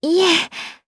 Talisha-Vox-Deny_Jp.wav